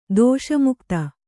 ♪ dōṣa mukta